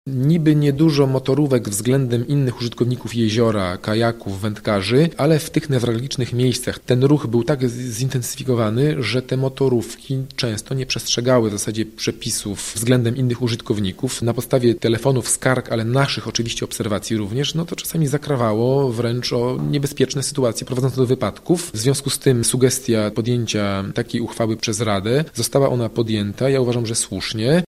’- Bardzo uważnie przyjrzeliśmy się sprawie i uznaliśmy, że jezioro Lubiąż jest na tyle duże, że np. w rejonie plaży czy ośrodków wypoczynkowych, motorówki pływać nie powinny, bo znajdą one dobre miejsce w innej części akwenu – tłumaczy Radosław Sosnowski, burmistrz Lubniewic.